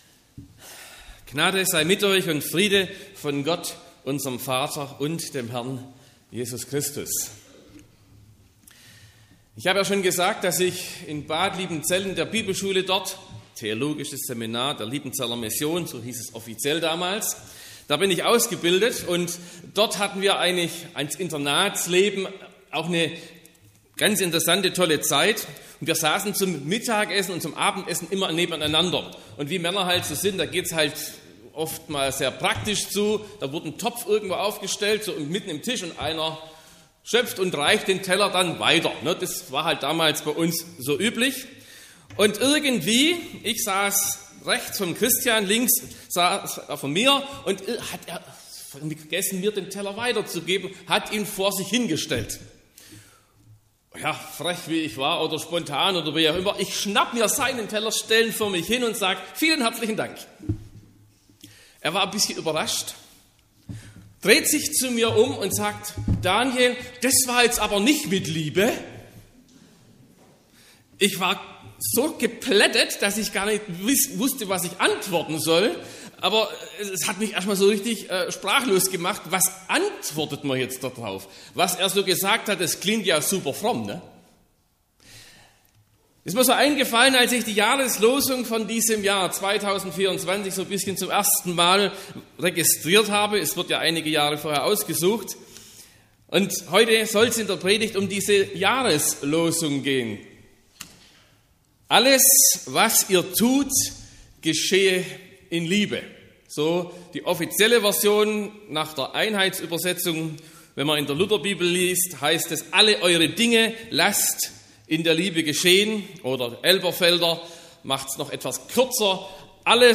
01.01.2024 – gemeinsamer Neujahrsfestgottesdienst
Predigt und Aufzeichnungen
Mitschnitt (Audio): Gottesdienst_2024_01_01_Predigt.mp3 (25,8 MB)